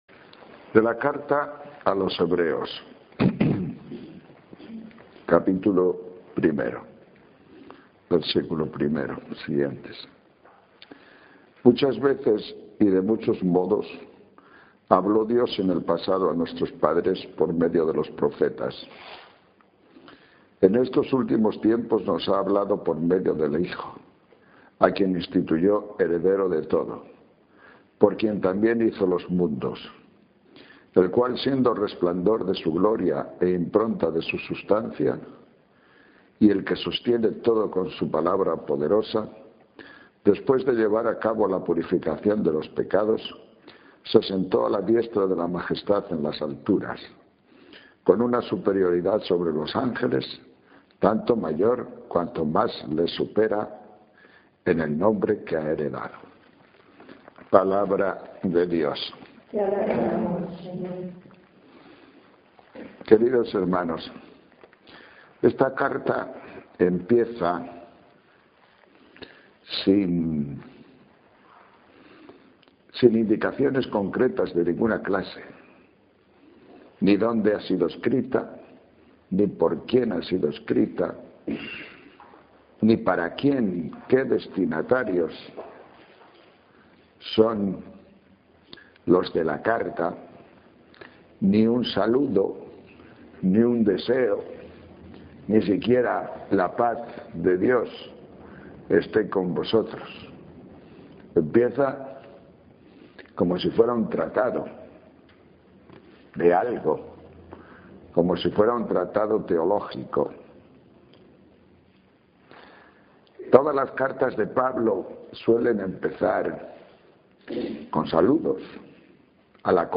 Ejercicios espirituales sobre "Hebreos" Lerma-2006